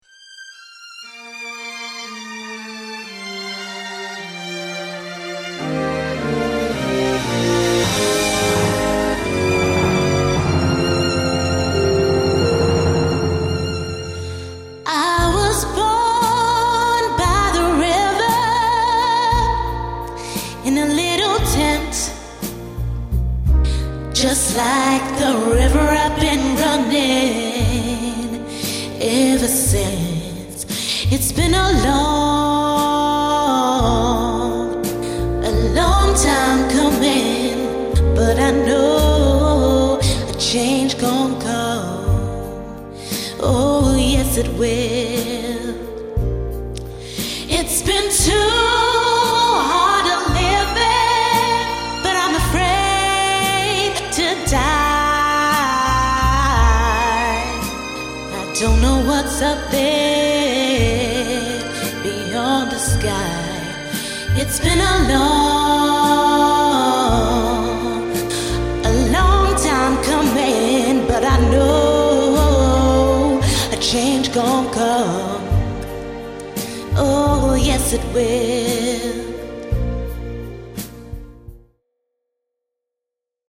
Singing Showreel
Female
London
Neutral British
Bright
Upbeat
Youthful